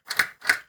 combo_unlock.ogg